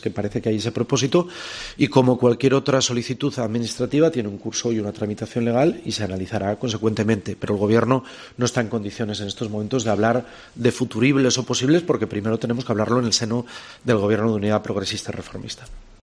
El presidente de Asturias habla sobre la posible implantación de la Universidad Europea en Gijón